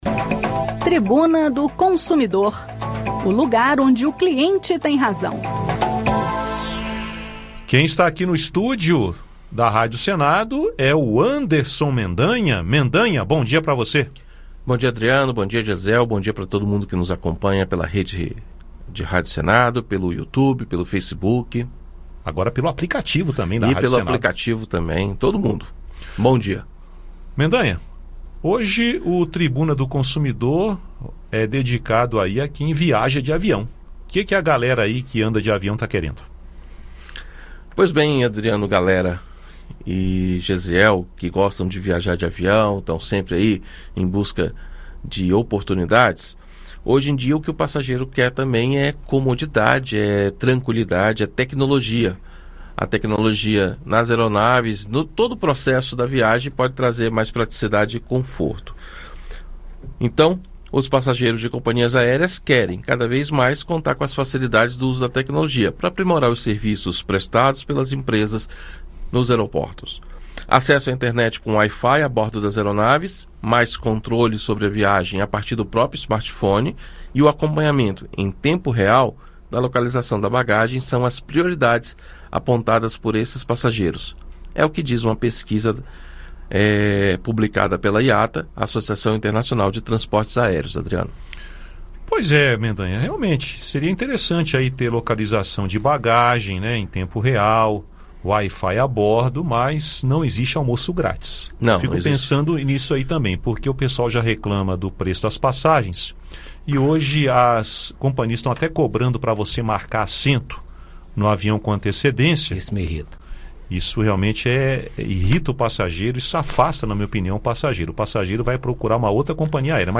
No "Tribuna do Consumidor" desta quinta-feira (31), o assunto são os desenvolvimentos tecnológicos que podem facilitar a vida dos passageiros de empresas aéreas. Ouça o áudio com o bate-papo.